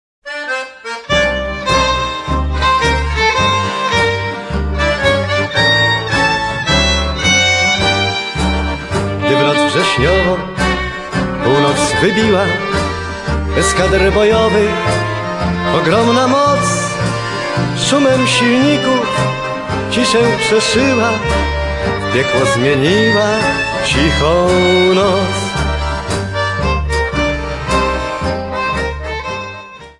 Polish songs